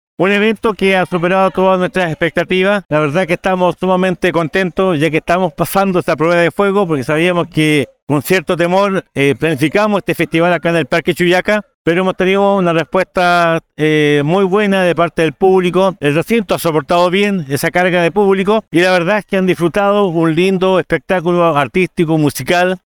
El alcalde de Osorno, Emeterio Carrillo, destacó la asistencia de las personas y recalcó que el Parque Chuyaca, escenario en el que por primera vez se realizaba este festival, es el adecuado para próximas ediciones y que se repetirá manteniendo un costo accesible para las personas.